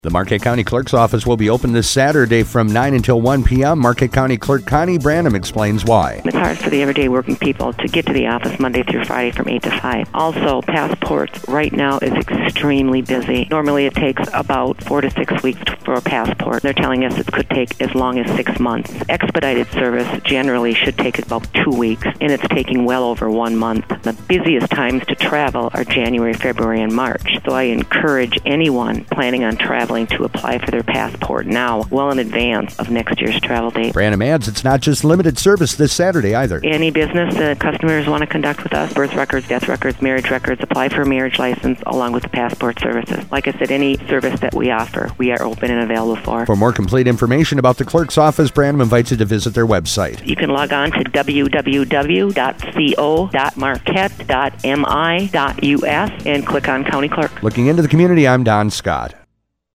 INTERVIEW:Connie Branam – Marquette County Clerks Office Saturday Hours
The Marquette County Clerks Office will be open this Saturday, June 9th, from 9am until 1pm. Marquette County Clerk Connie Branam tells us more